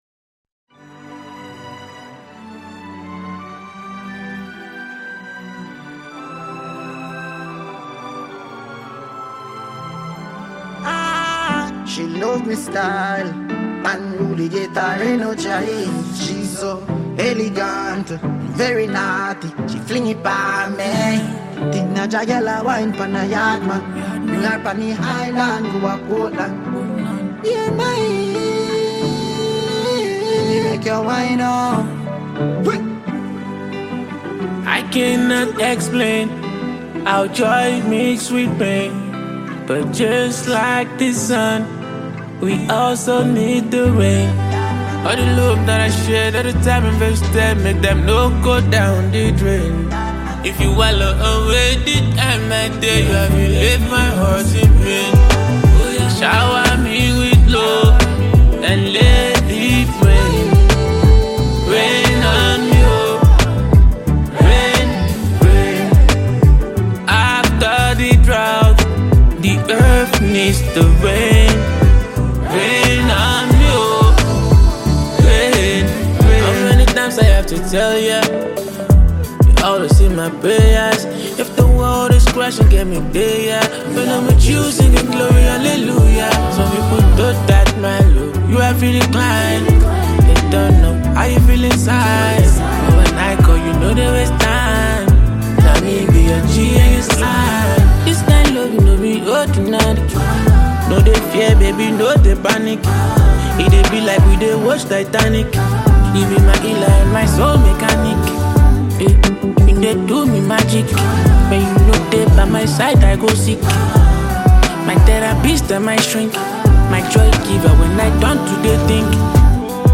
African Music Genre: Afrobeats Released